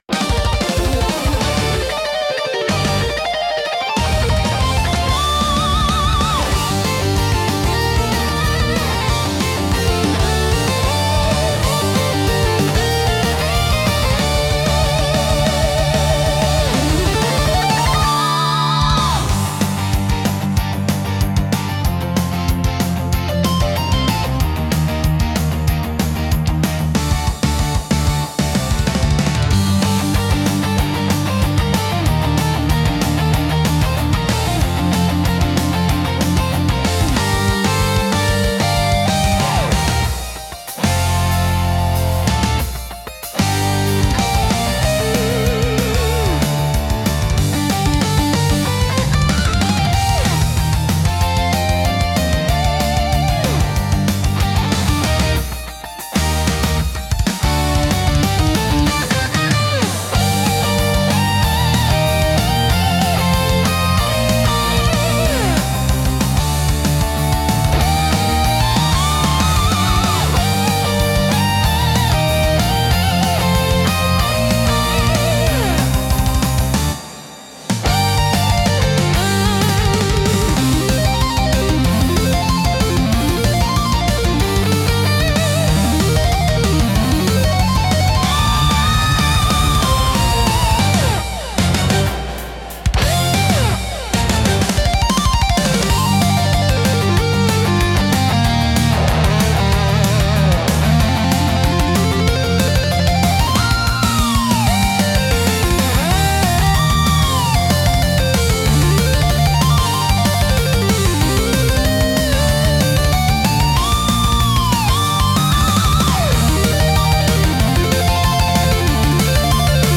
BGMとしては、ゲームや映像の戦闘シーンに最適で、激しいアクションと感情の高まりを盛り上げます。